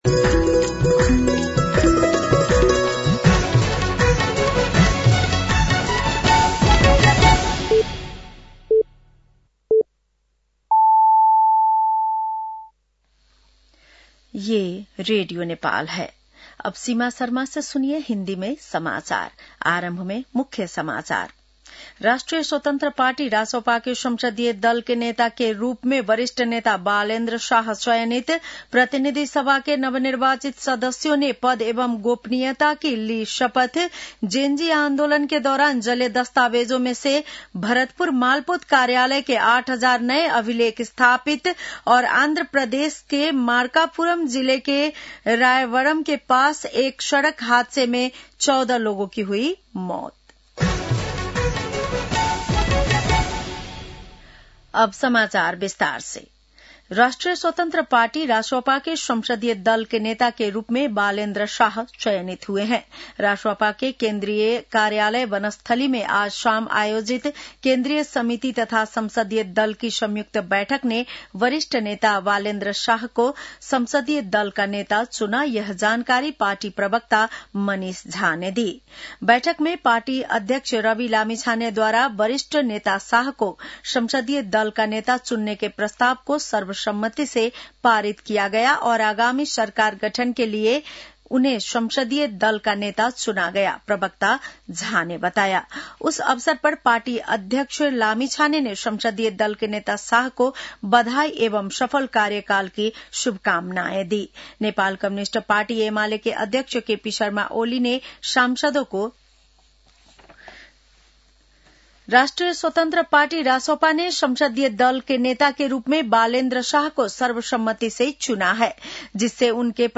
बेलुकी १० बजेको हिन्दी समाचार : १२ चैत , २०८२